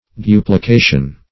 duplication \du`pli*ca"tion\, n. [L. duplicatio: cf. F.